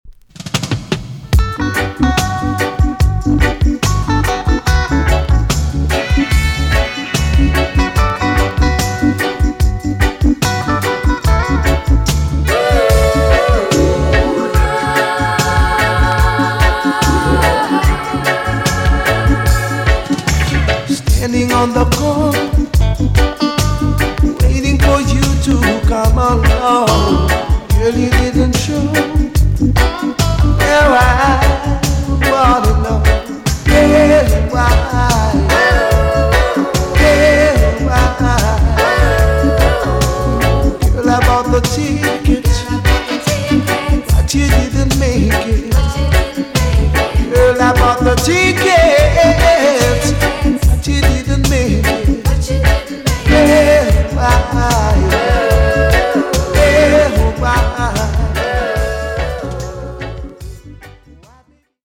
TOP >DISCO45 >80'S 90'S DANCEHALL
EX- 音はキレイです。